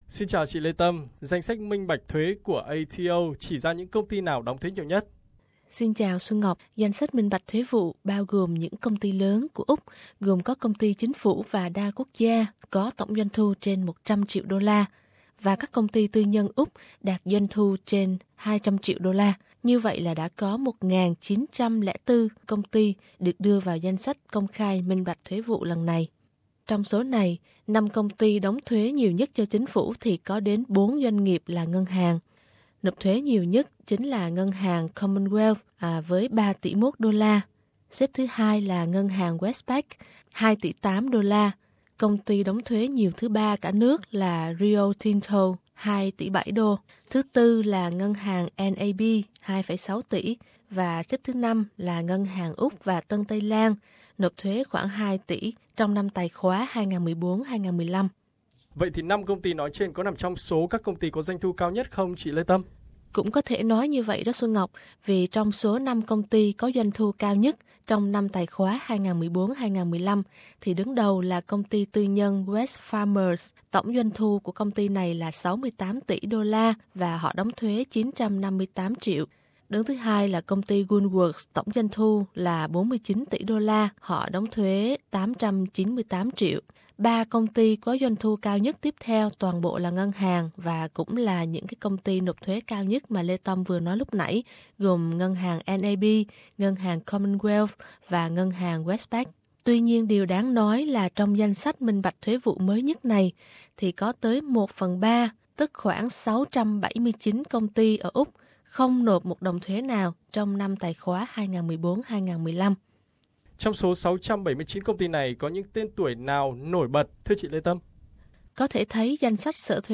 phần hỏi đáp